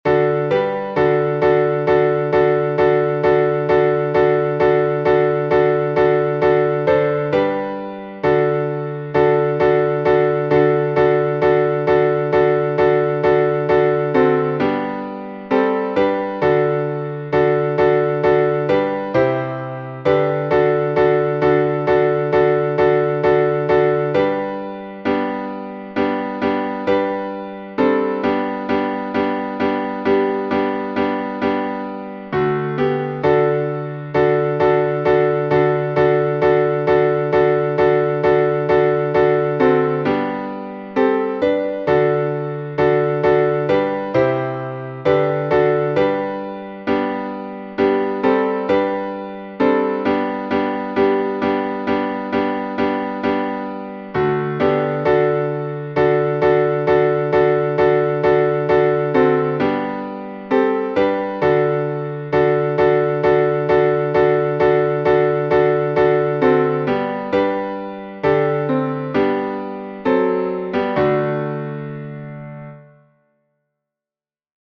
Back to Menu · Small Litany · Third Antiphon (Beatitudes) Kievan chant Boris Ledkovsky Kievan Chant PDF · MP3 · MSCZ · Video · To the Top To the Top · Back to Menu · Small Litany · Third Antiphon (Beatitudes)
kievan_chant_ledkovsky.mp3